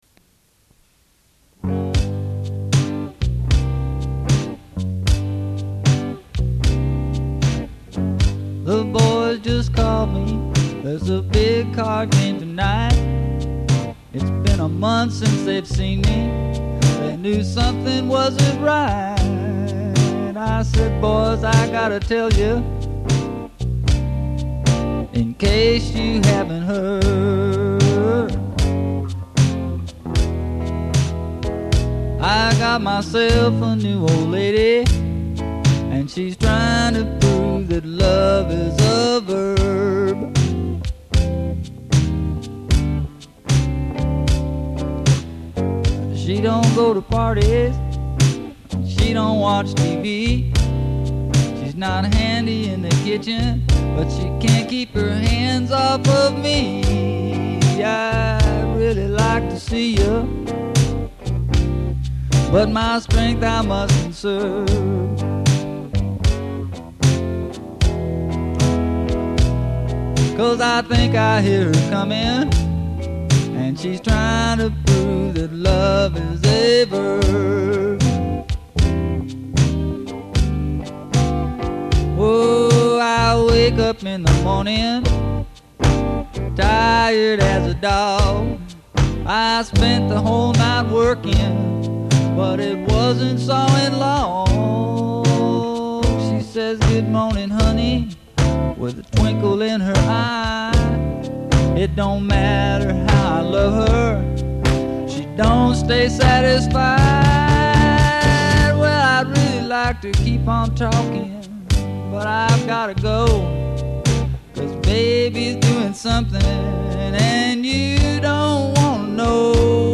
4 TRACK DEMO